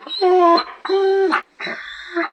bdog_die_2.ogg